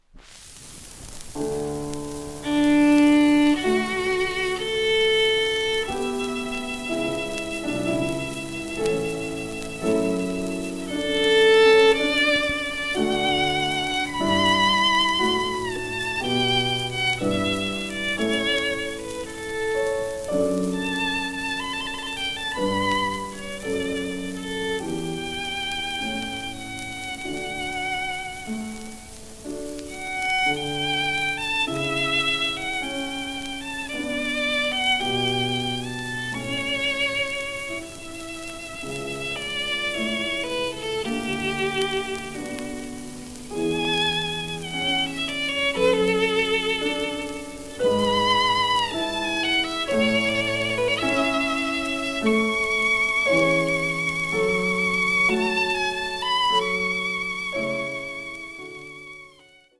1937年2月2日ロンドン録音